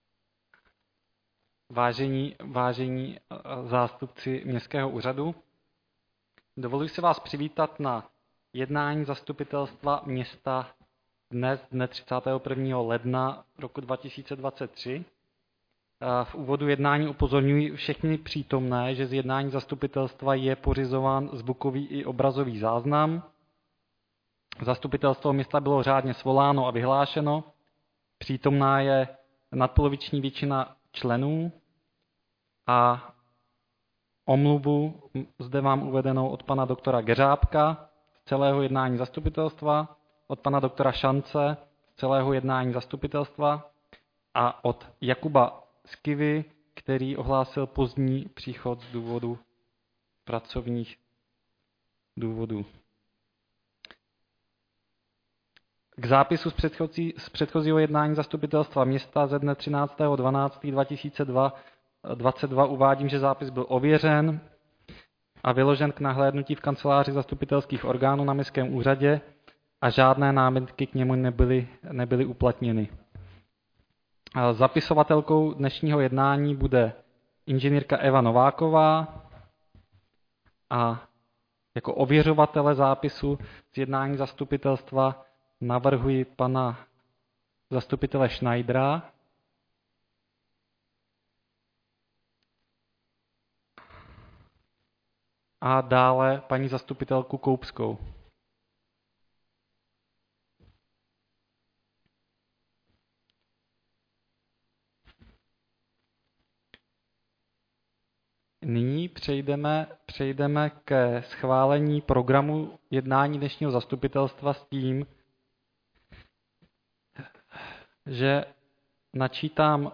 Zastupitelstvo KH 31.1.23